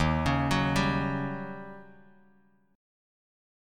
Eb7#9 Chord
Listen to Eb7#9 strummed